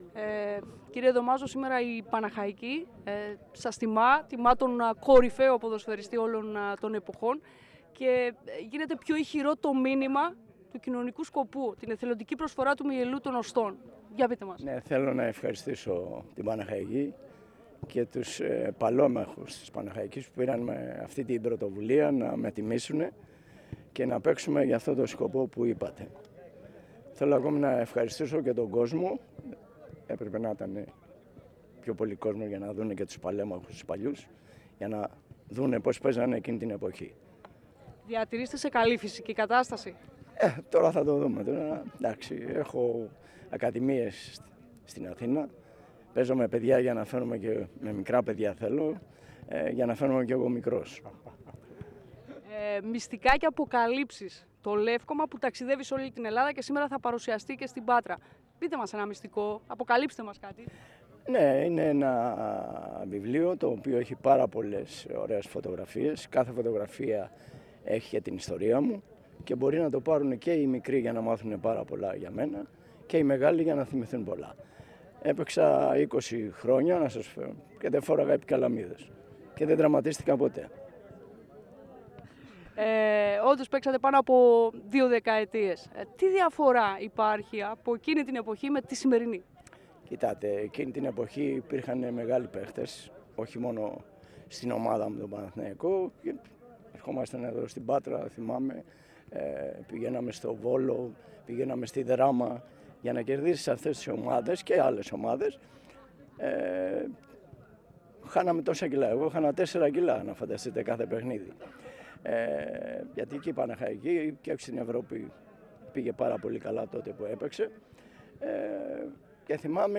Ο Μίμης Δομάζος πριν από την έναρξη του αγώνα σε δηλώσεις του στην ΕΡΤ ΠΑΤΡΑΣ αναφέρθηκε  στην πρωτοβουλία των παλαίμαχων της Παναχαϊκής , στις διαφορές του χθες  και του σήμερα. Ο «στρατηγός» μίλησε επίσης  για το βιβλίο- λεύκωμα «Μίμης Δομάζος: Μυστικά και άλλες αποκαλύψεις», τον Παναθηναϊκό, τη μεγάλη Παναχαϊκή και για το φιλανθρωπικό σκοπό του φιλικού αγώνα.